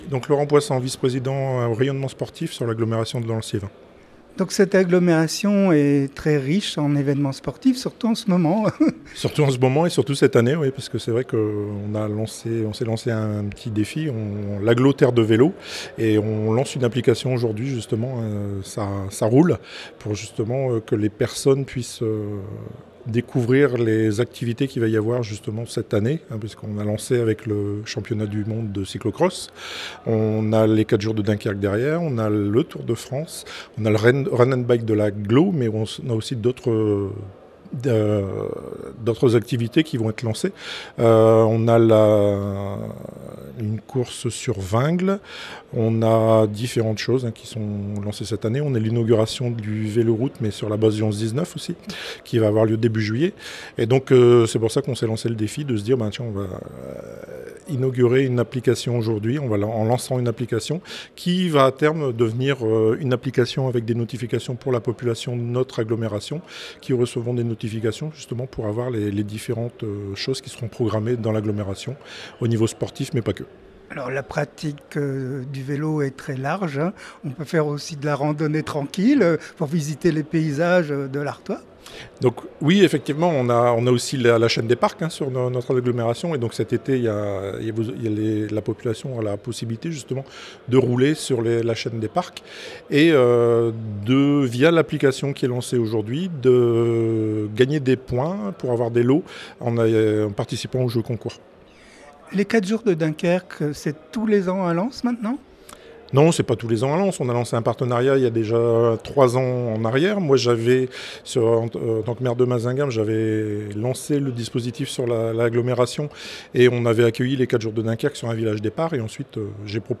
- interview de Laurent POISSANT, Vice-Président de la CALL, Chargé du rayonnement sportif